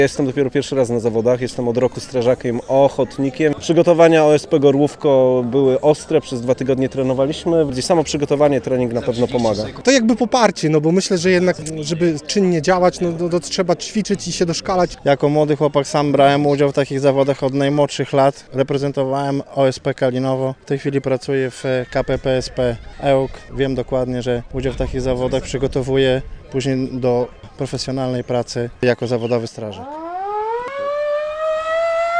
-Takie zawody pomagają nam w codziennej pracy, mówią uczestnicy z którymi rozmawiał reporter Radia 5.